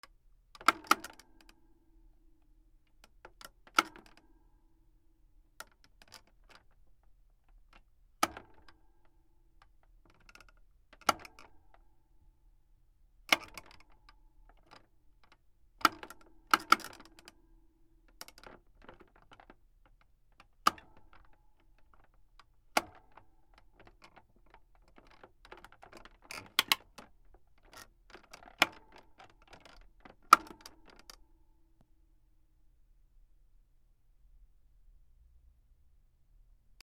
扇風機(首関節部分を動かす)
/ M｜他分類 / L10 ｜電化製品・機械